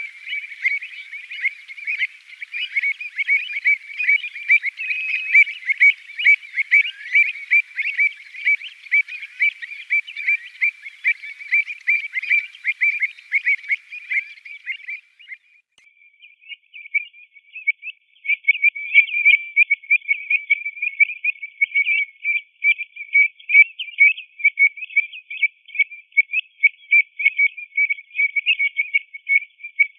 Avocette élégante - Mes zoazos
avocette-elegante.mp3